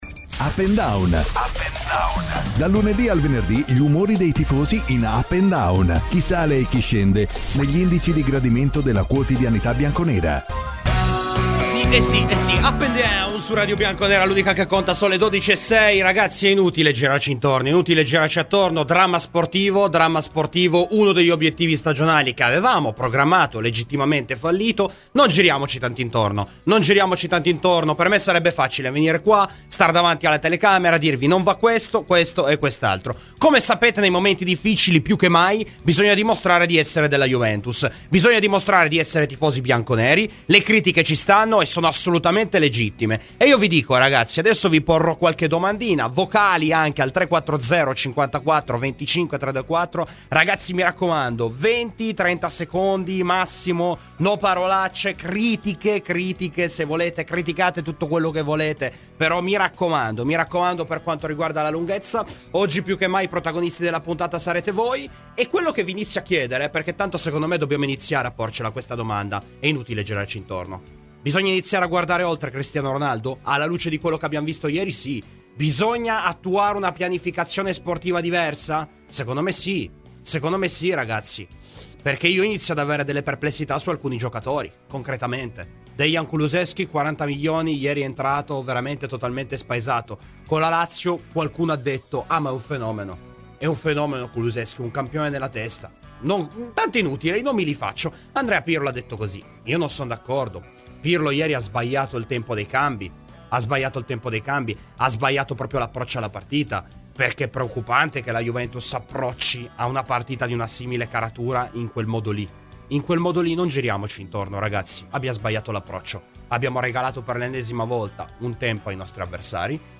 Clicca sul podcast in calce per ascoltare la trasmissione integrale.
e voce ai tifosi.